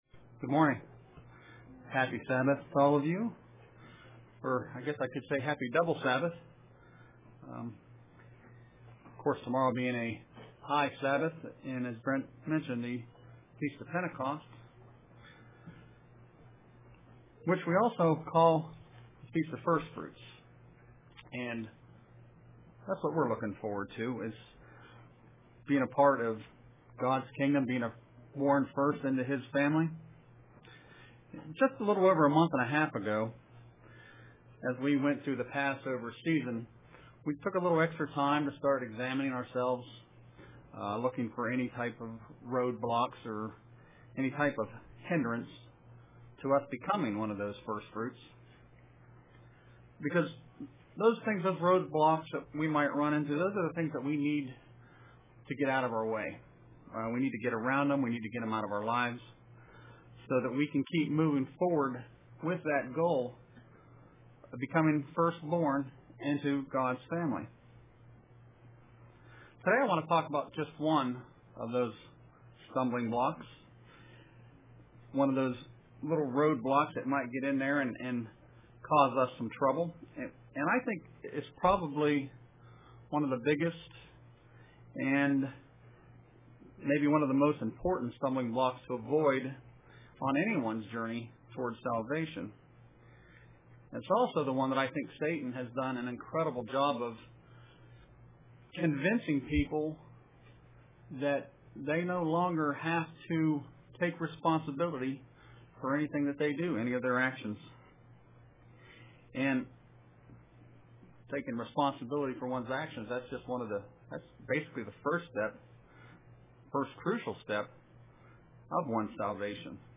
Print Sorrow Leads to Repentance UCG Sermon Studying the bible?